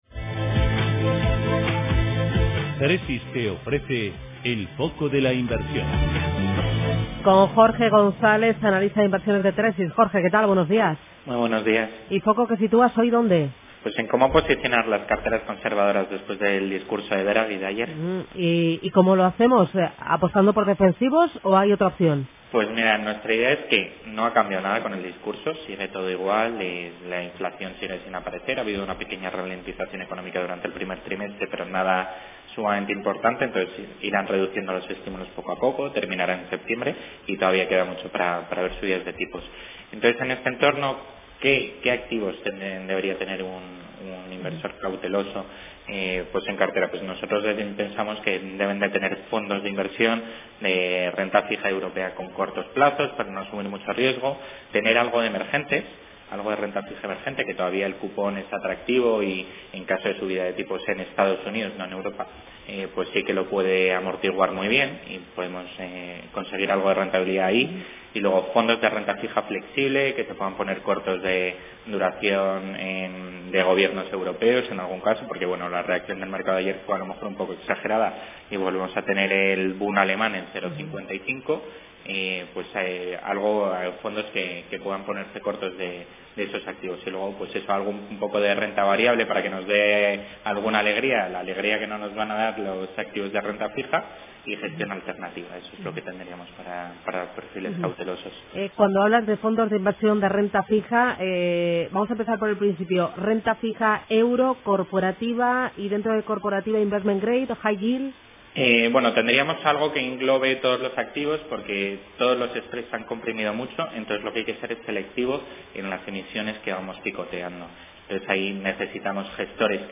En la radio
En Radio Intereconomía todas las mañanas nuestros expertos analizan la actualidad de los mercados.